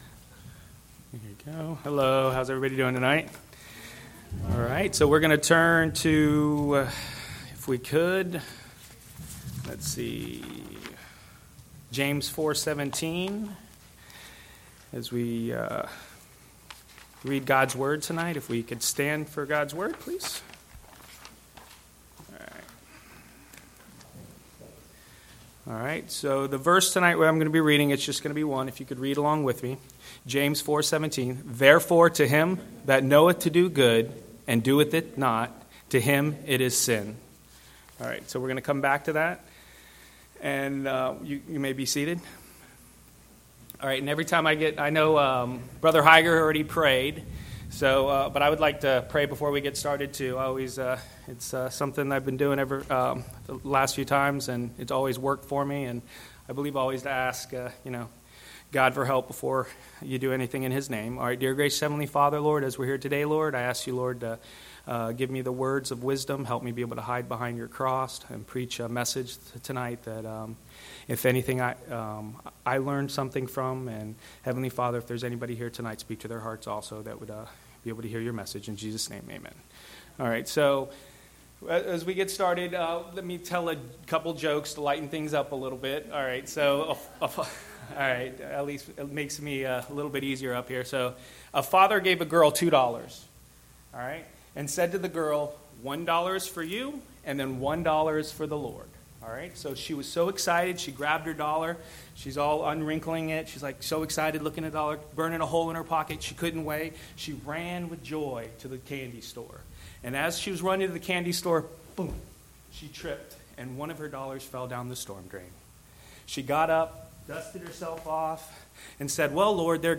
Bible Text: James 4:17 | Preacher